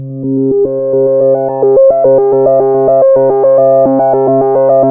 JUP 8 C4 11.wav